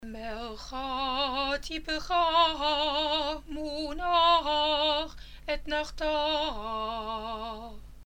The sound of the Haftarot is the sound of forgiveness, even if the texts may be admonitions to call us to our tasks.
The special Haftarah sound is created by specific modulations of the tunes of the signs.
Etnahtah Clause (Haftarah)